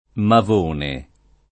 [ mav 1 ne ]